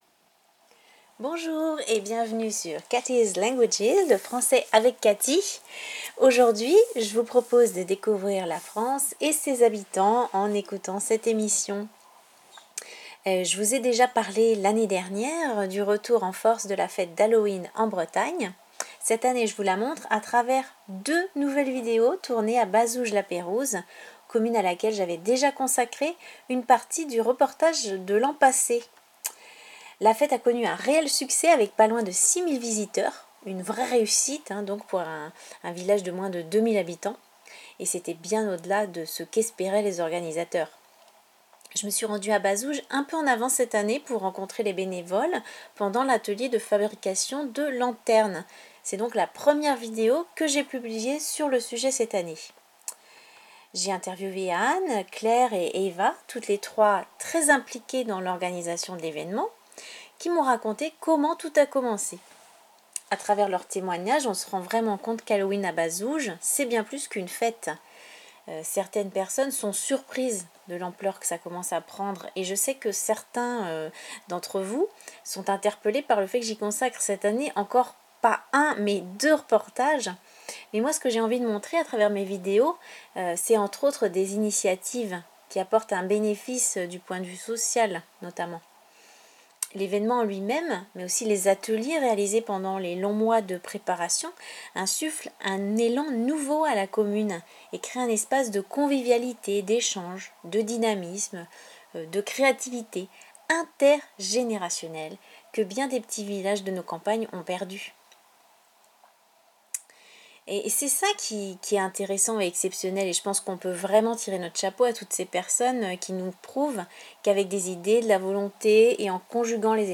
Halloween en Bretagne: reportages, interviews, expressions idiomatiques en contexte et poésie récitée